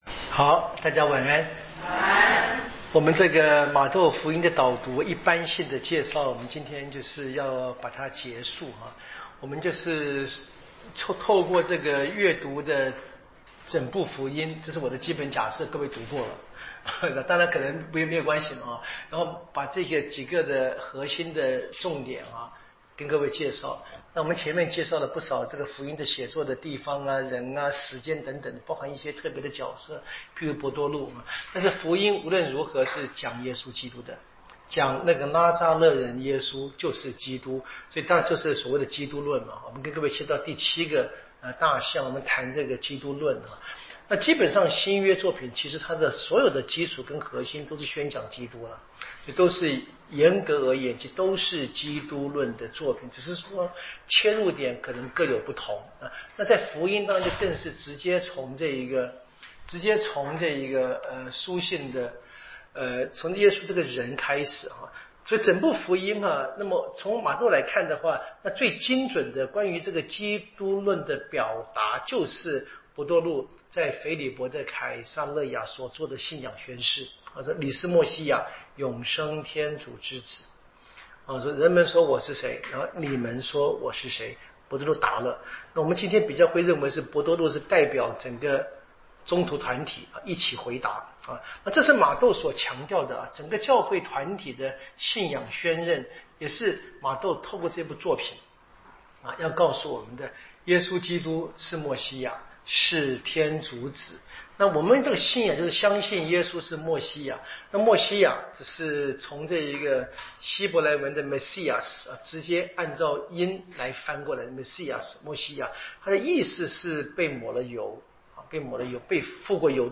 【圣经讲座】